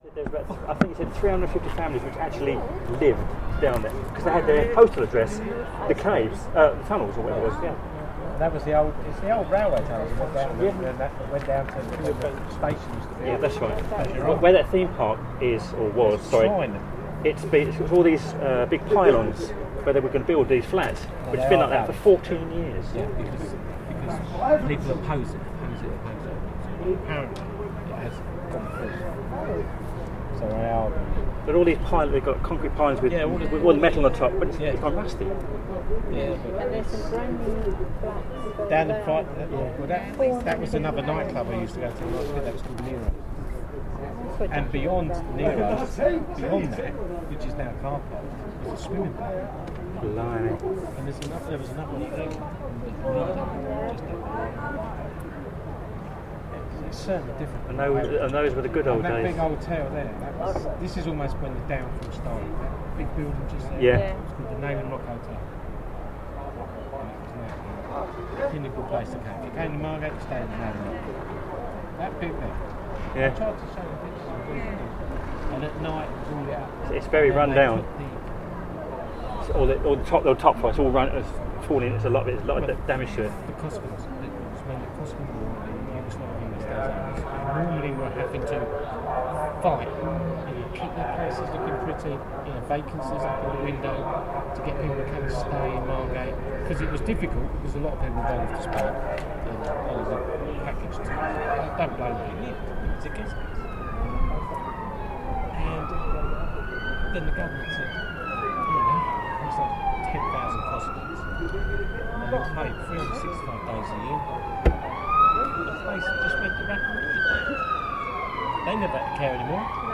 A conversation at the Lighthouse Bar about how things used to be back in the day before it all changed in Margate.
Part of the Cities and Memory Margate sound map for Dreamland.